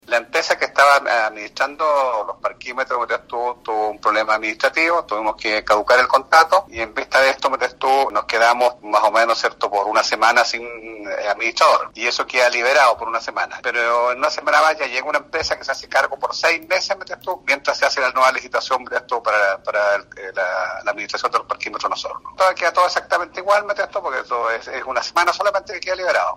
Dicha información fue corroborada por el alcalde de la comuna, Jaime Bertín quien en conversación con Radio Sago señaló que la empresa que administraba tuvo problemas, por ende, se caducó de emergencia el contrato buscando a otra entidad que se haga cargo por seis meses.